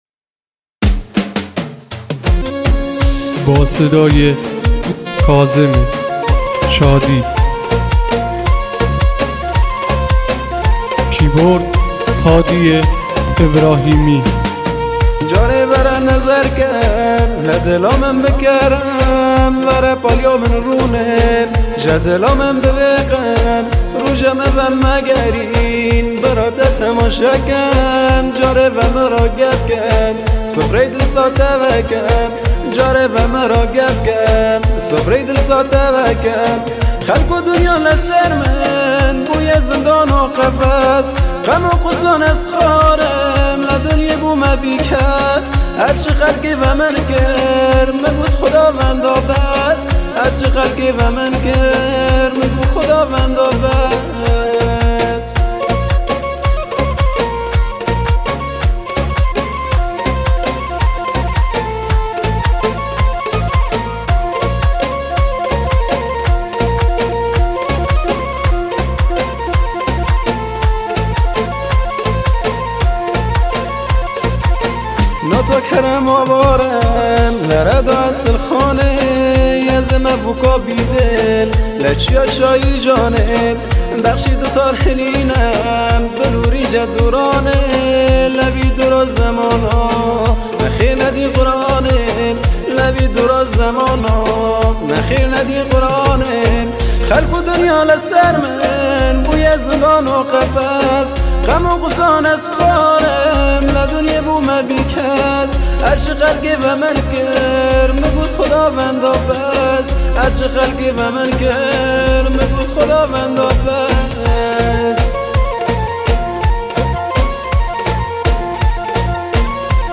دانلود آهنگ کرمانجی